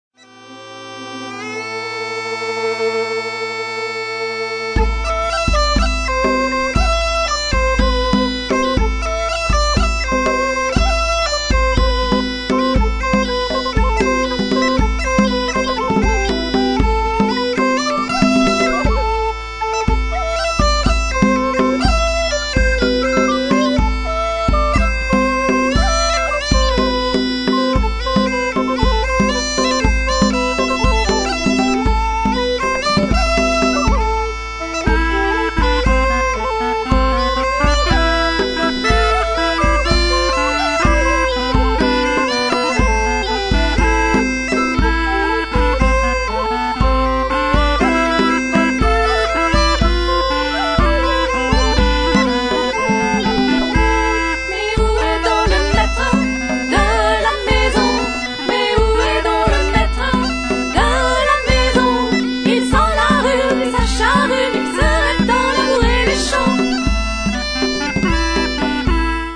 Extraits de branle